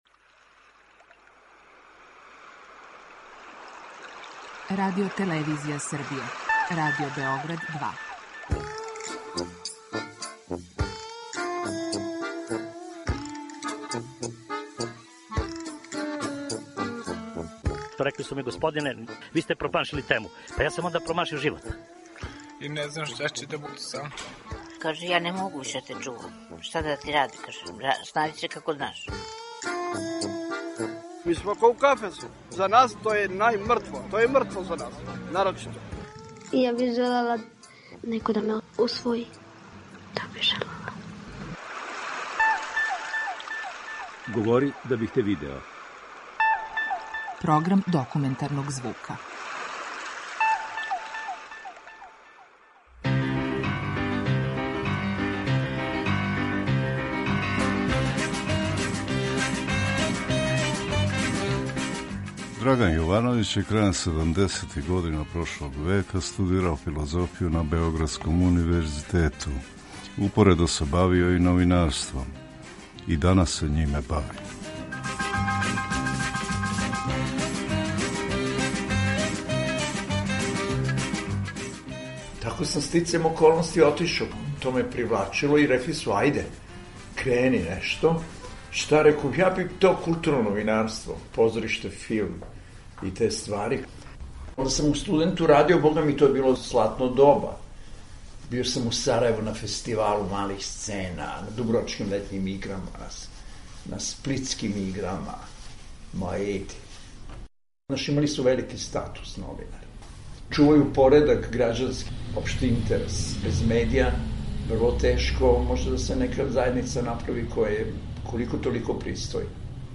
Документарни програм
Група аутора Серија полусатних документарних репортажа